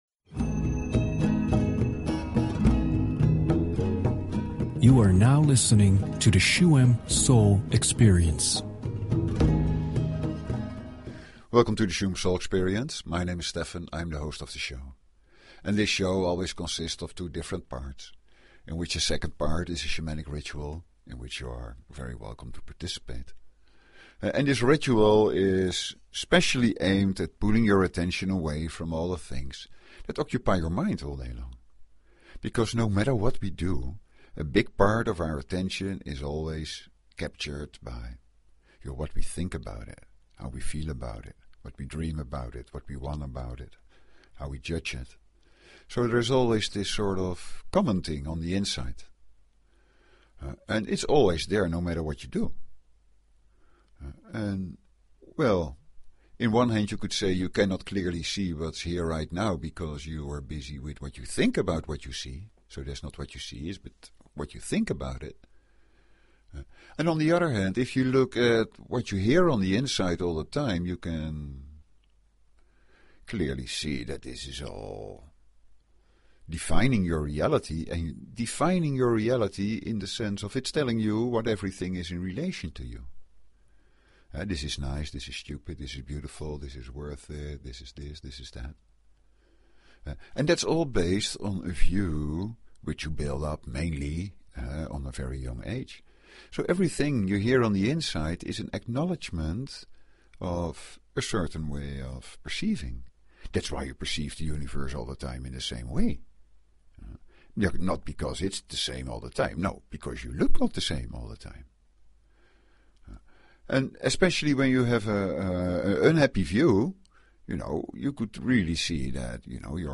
Talk Show Episode, Audio Podcast, Shuem_Soul_Experience and Courtesy of BBS Radio on , show guests , about , categorized as
The shamanic ritual in the second part of the show supports this movement within yourself to forget about theories and start to experience the inner peace that is inside of everyone.
Let the energy of the ritual and the sounds of the instruments flow through you and you may touch upon that inner peace to carry you in daily life.